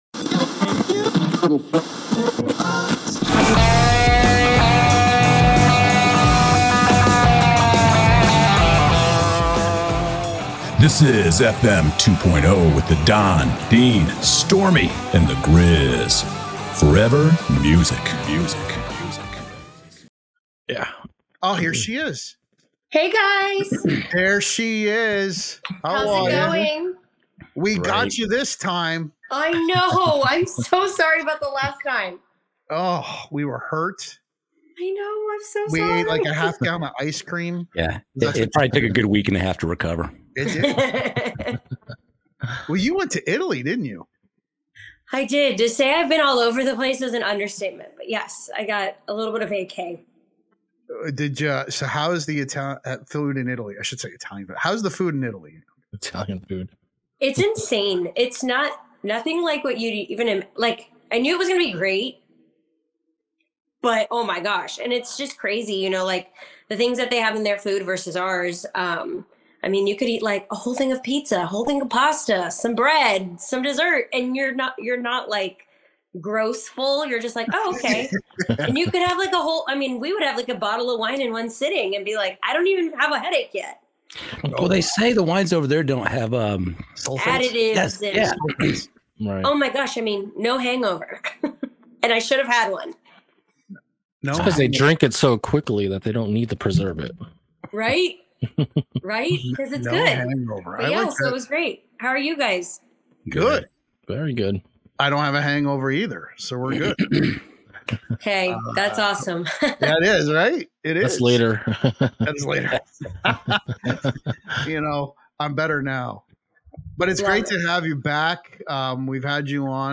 Regional Mexican music, Tejano music, and country music are very similar. The differences are in the instrumentation and we get into a conversation about this.